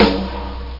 Bonham Snare Sound Effect
Download a high-quality bonham snare sound effect.
bonham-snare.mp3